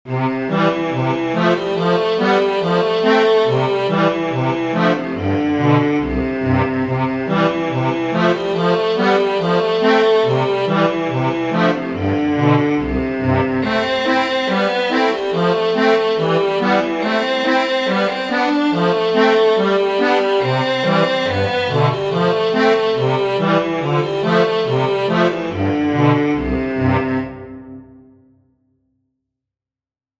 Konzertina